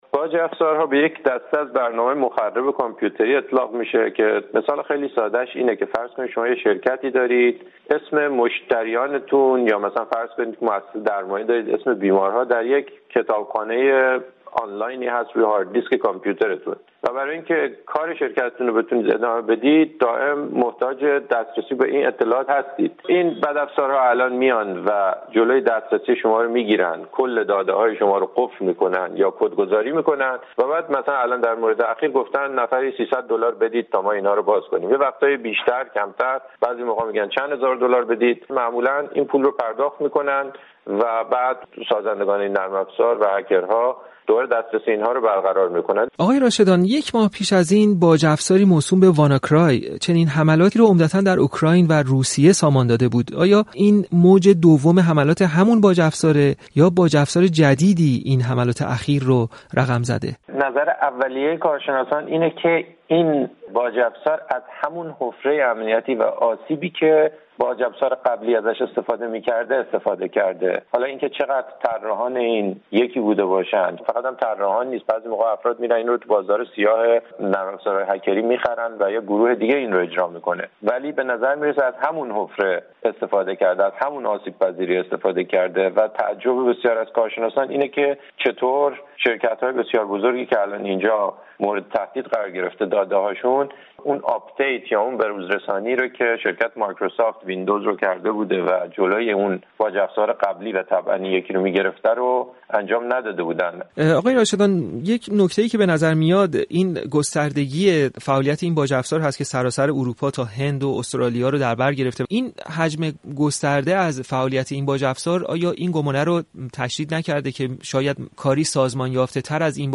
گفتگو کرده است.